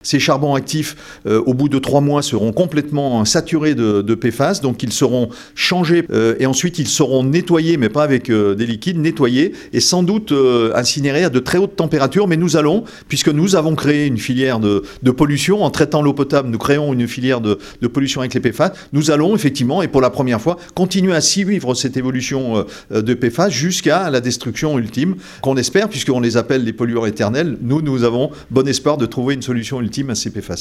Christian Heison, maire de Rumilly: